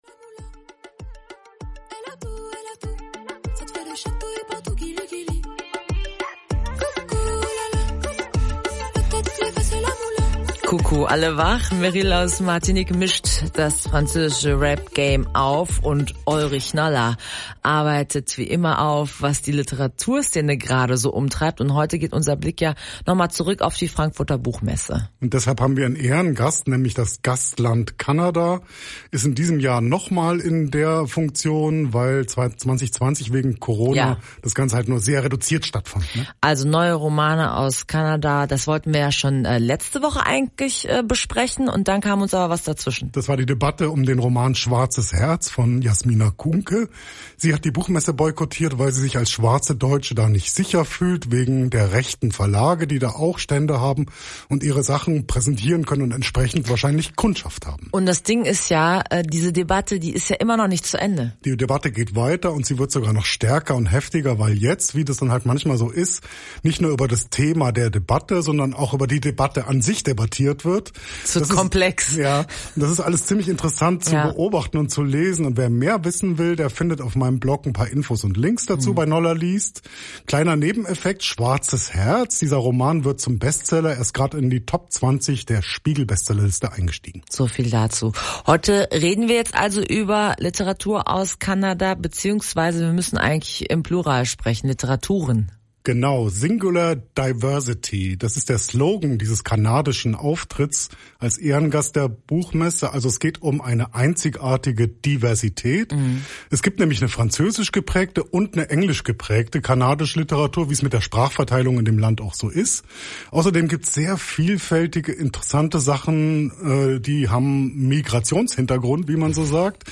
Und das sind die drei kanadischen Romane “mit Migrationshintergrund”, die wir Anfang November (im Gespräch oben) etwas genauer vorgestellt haben: